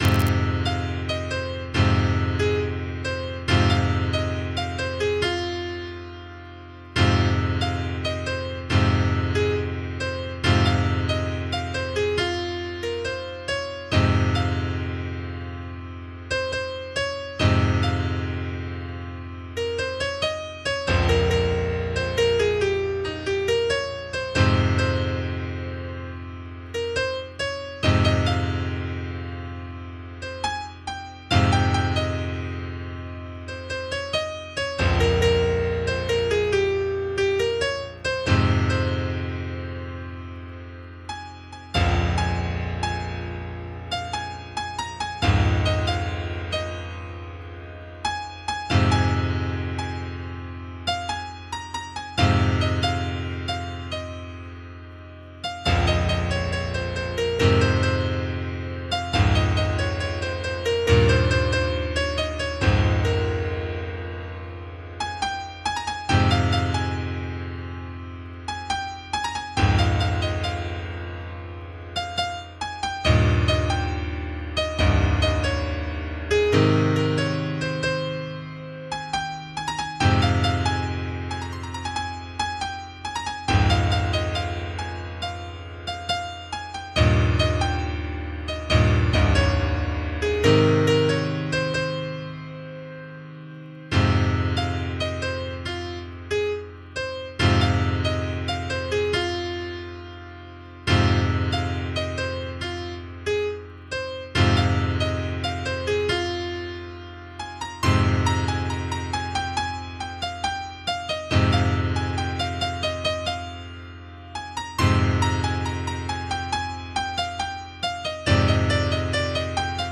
MIDI 5.5 KB MP3 (Converted) 2.79 MB MIDI-XML Sheet Music